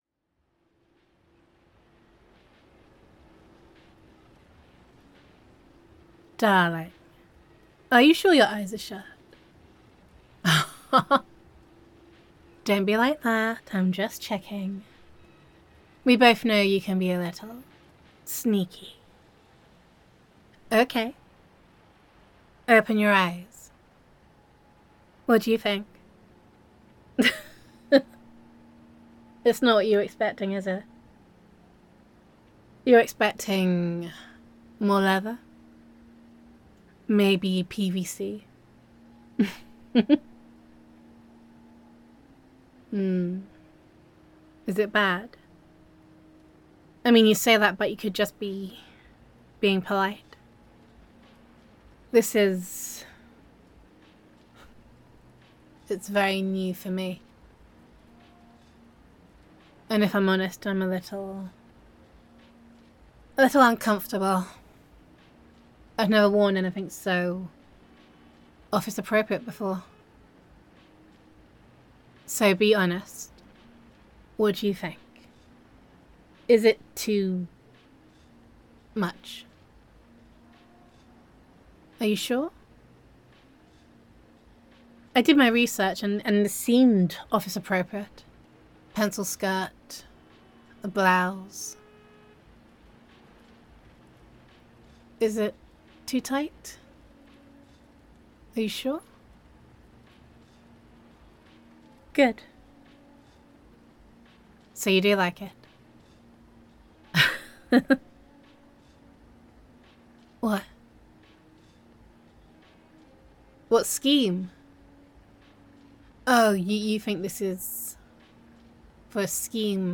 [F4A] You Changed Everything
[Girlfriend Roleplay]